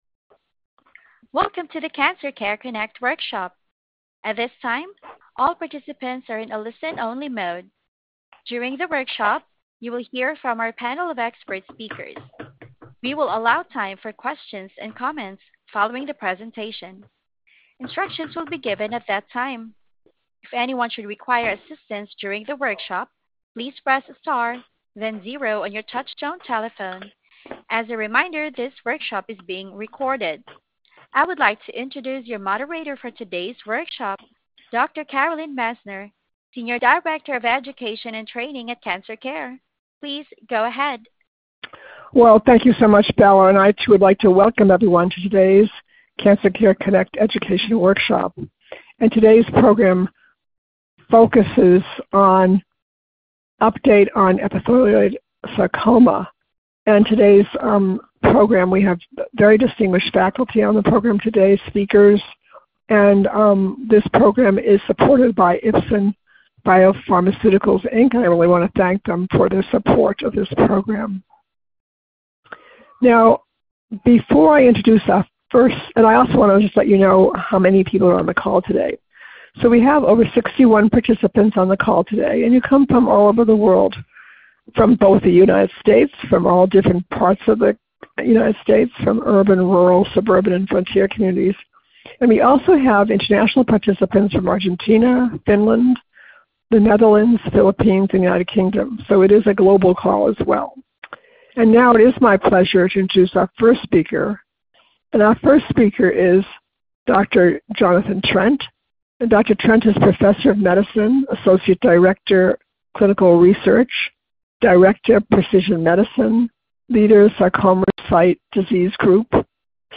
Questions for Our Panel of Experts
This workshop was originally recorded on July 18, 2025.